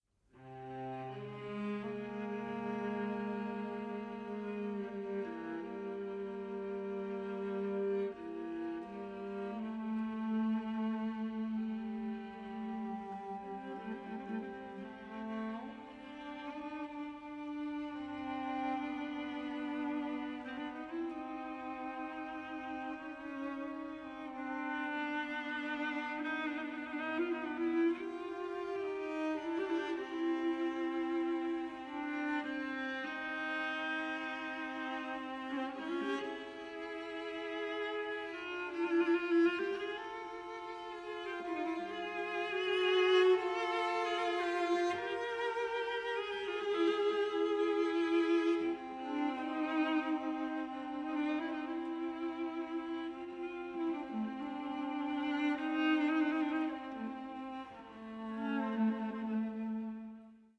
24-bit stereo